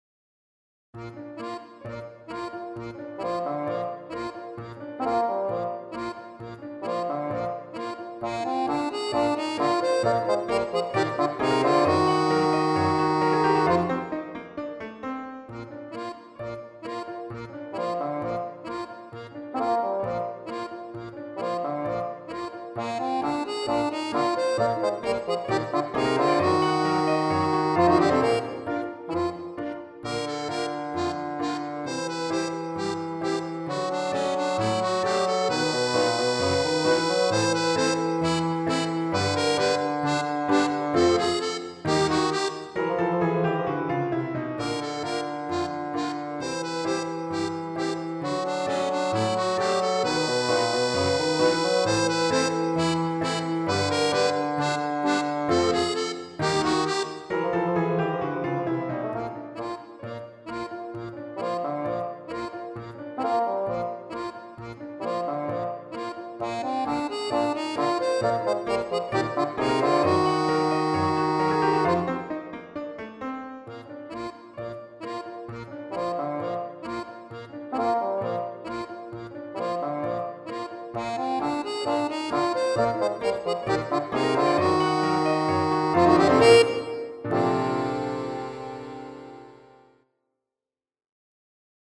Trio Accordion - Piano - Basson
For Accordion solo and Ensemble